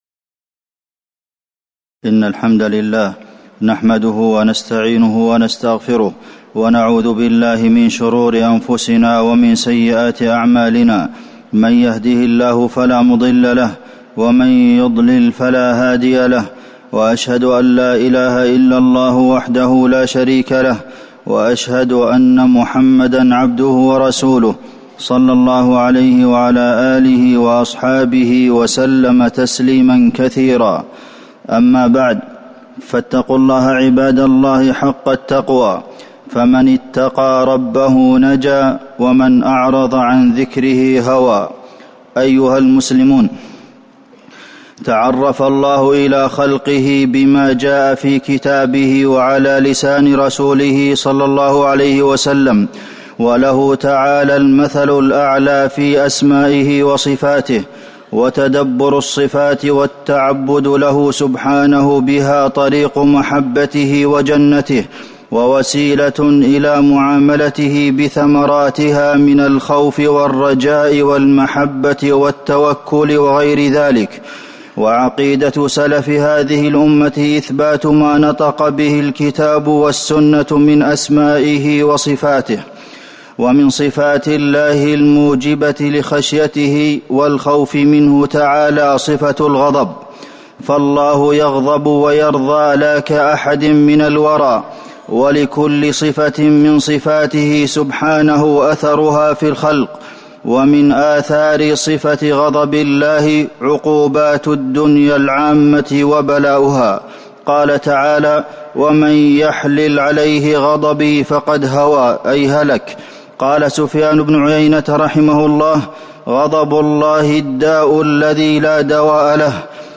تاريخ النشر ٩ ذو القعدة ١٤٤٠ هـ المكان: المسجد النبوي الشيخ: فضيلة الشيخ د. عبدالمحسن بن محمد القاسم فضيلة الشيخ د. عبدالمحسن بن محمد القاسم غضب الرب سبحانه